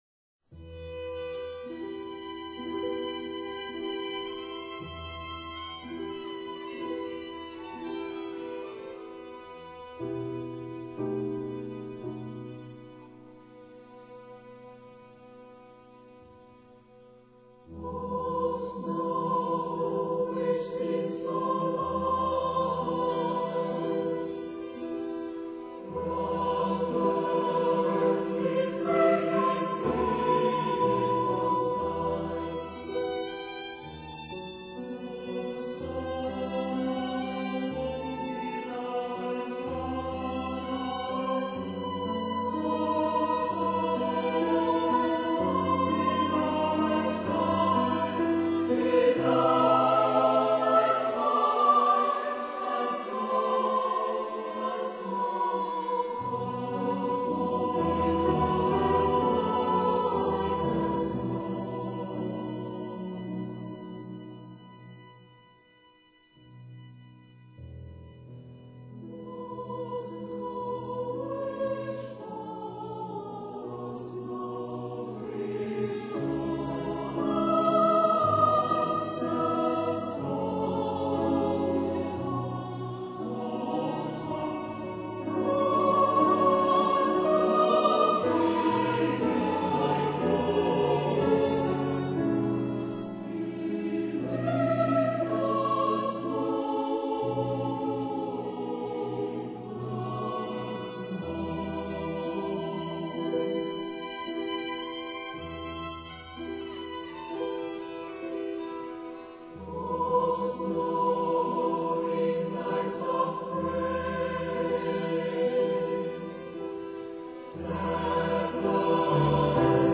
、大家先听一遍录音，这是用英文演唱的。